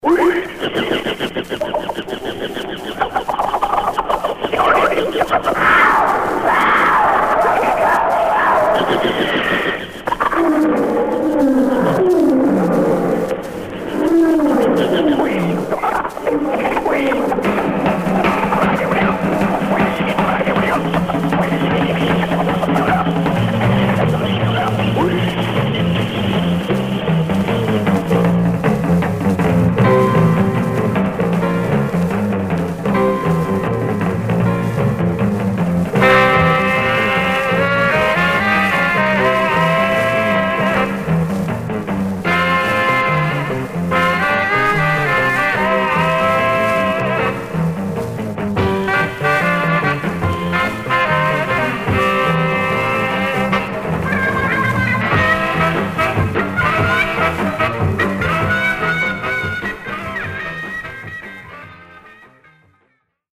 Mono
R&B Instrumental